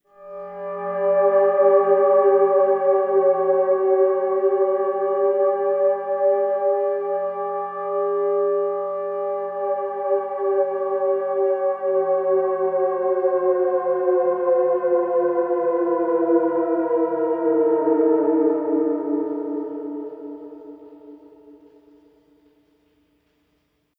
Long Space.wav